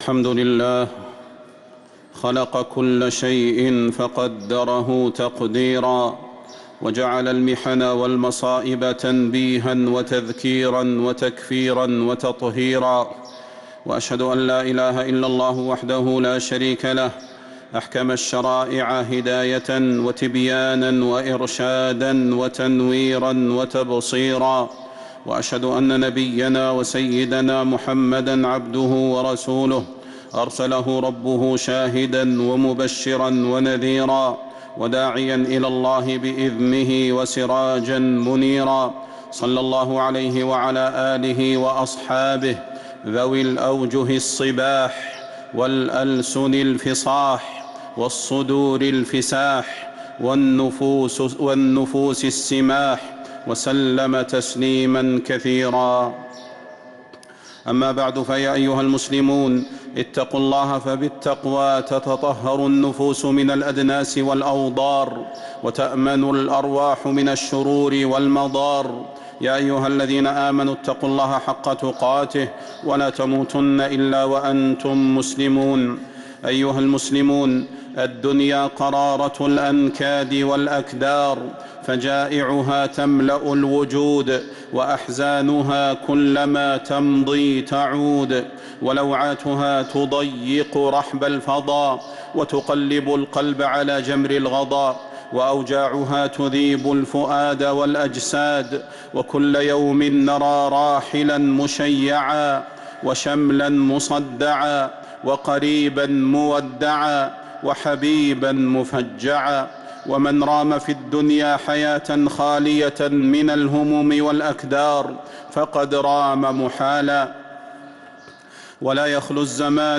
خطبة الجمعة 8-8-1446هـ | Khutbah Jumu’ah 7-2-2025 > خطب الحرم النبوي عام 1446 🕌 > خطب الحرم النبوي 🕌 > المزيد - تلاوات الحرمين